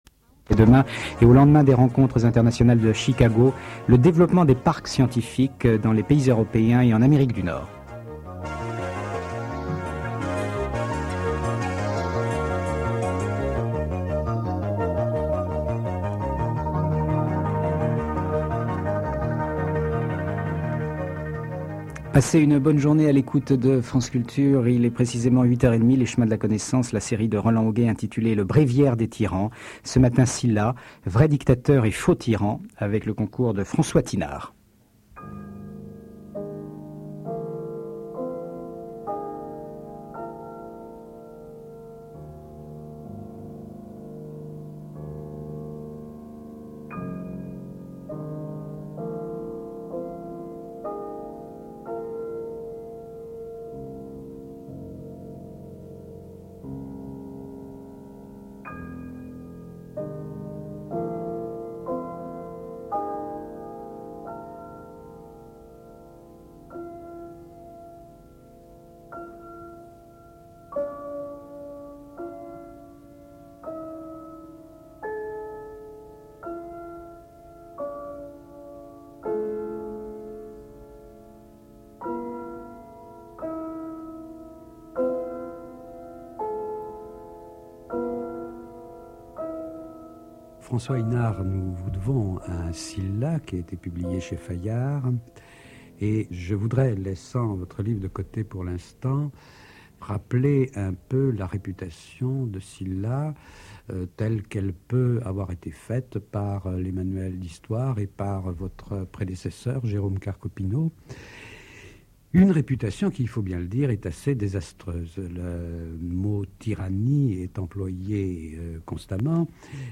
Entretiens qui retracent l’histoire de la tyrannie de la Grèce archaïque à la Rome impériale.